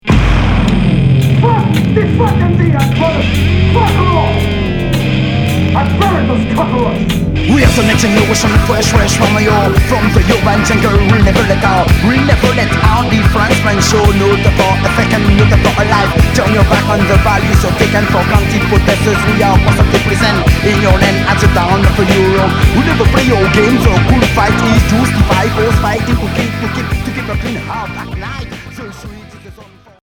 Reggae rock fusion